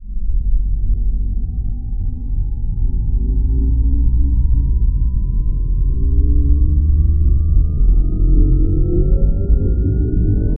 WarpAcceleration.wav